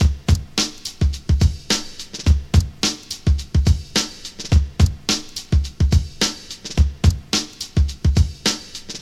• 106 Bpm Drum Loop Sample C# Key.wav
Free breakbeat sample - kick tuned to the C# note. Loudest frequency: 1436Hz
106-bpm-drum-loop-sample-c-sharp-key-9Ku.wav